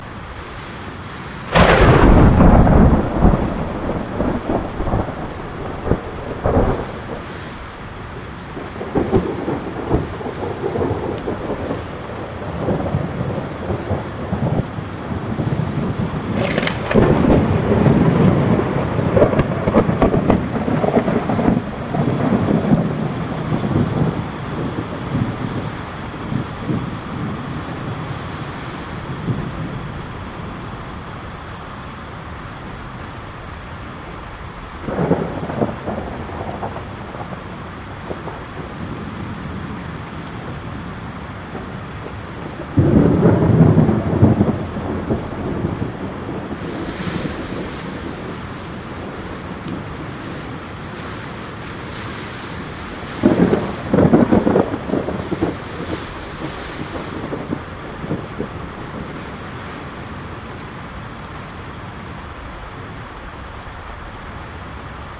Подборка передает всю мощь природного явления: свист ветра, грохот падающих предметов, тревожную атмосферу.
Грозовой гул урагана